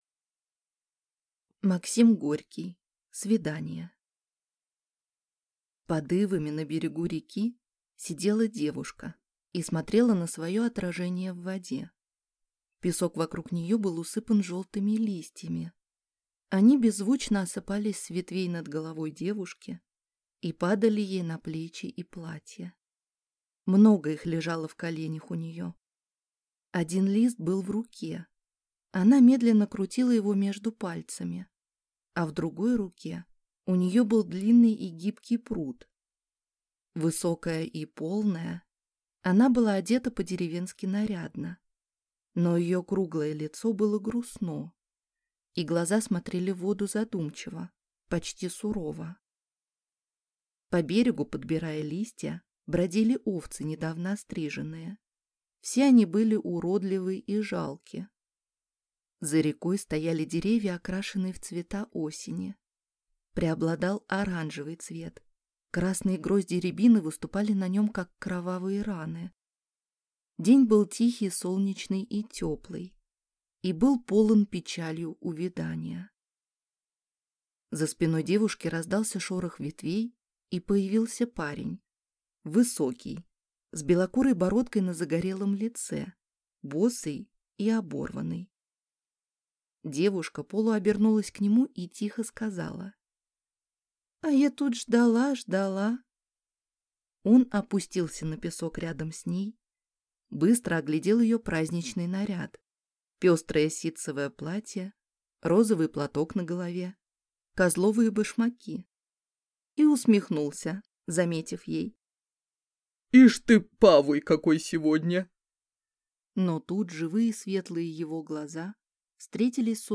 Аудиокнига Свидание | Библиотека аудиокниг
Читает аудиокнигу